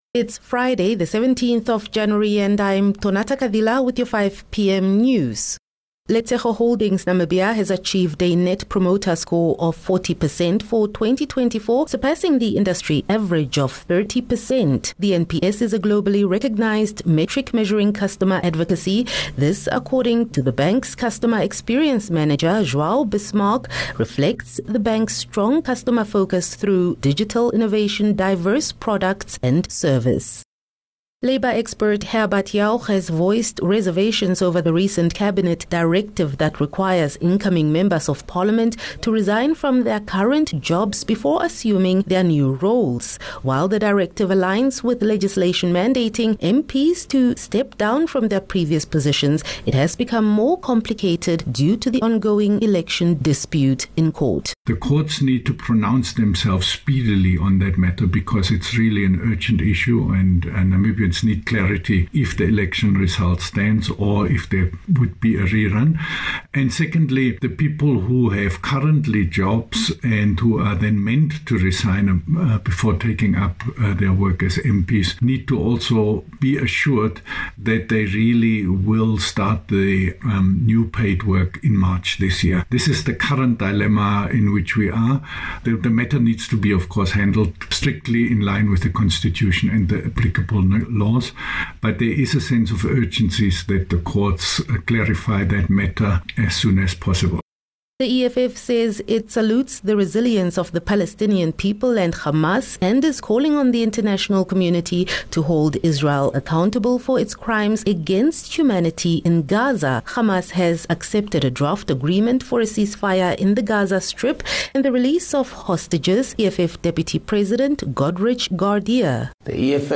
Daily bulletins from Namibia's award winning news team. Independent, Accurate, and On-Time